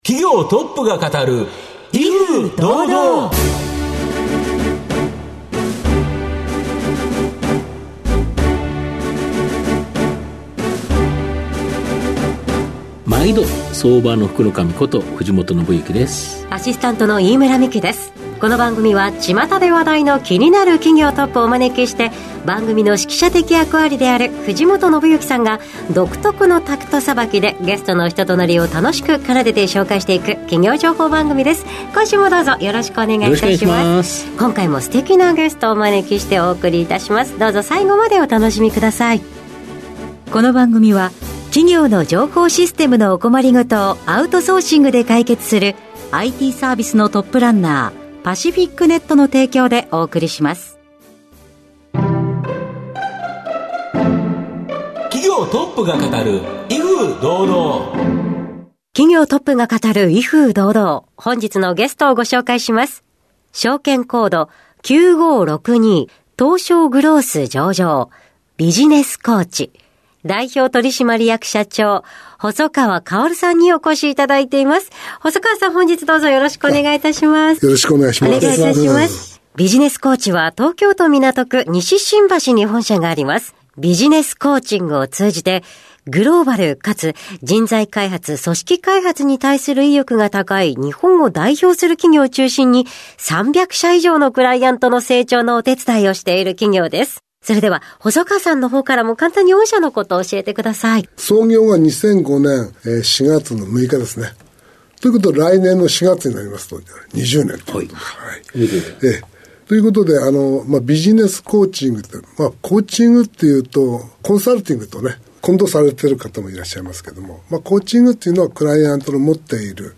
経営トップをゲストにお招きして事業展望や経営哲学などをうかがいつつ、トップの人となりにも迫るインタビュー番組です。